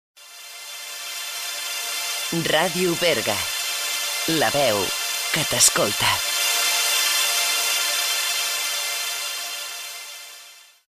Indicatiu de nit